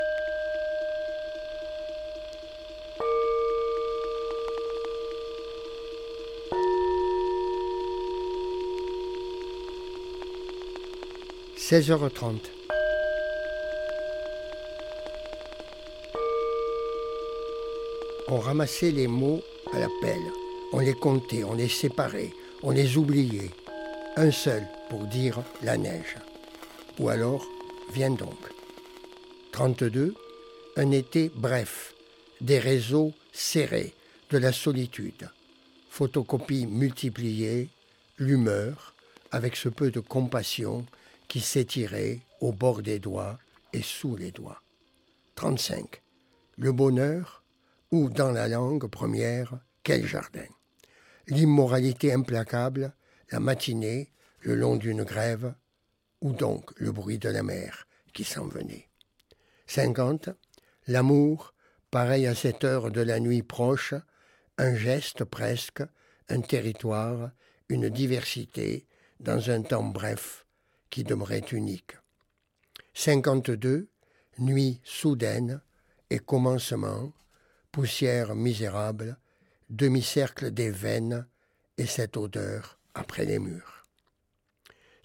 lire le début d'un poème